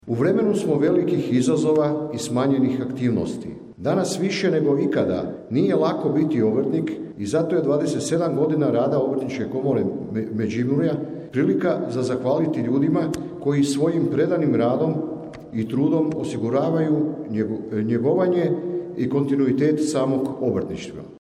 Na svečanosti održanoj u Prelogu Obrtnička komora Međimurske županije obilježila je 27 godina rada i tom prilikom nagrađeni su uspješni obrtnici, o čemu smo izvijestili u našem programu.
Domaćin, gradonačelnik Ljubomir Kolarek istaknuo je: